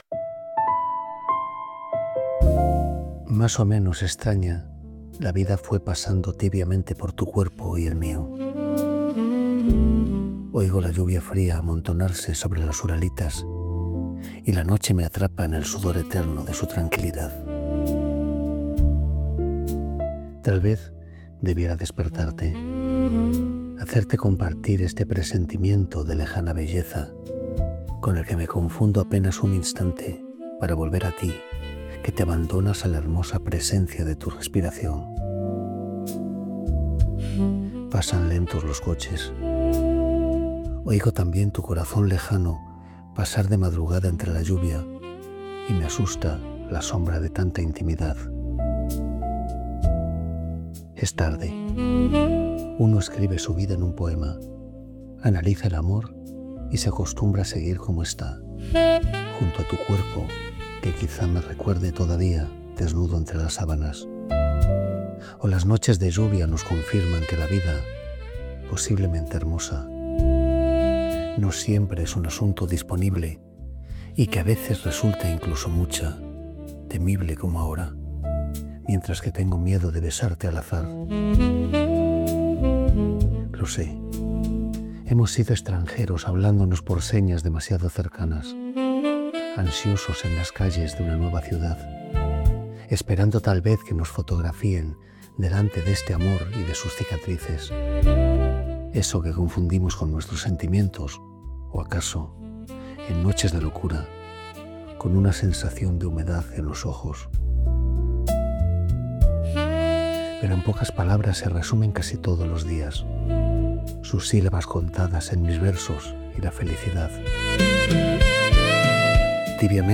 Luis-Garcia-Montero.-En-los-dias-de-lluvia-enhanced-music.mp3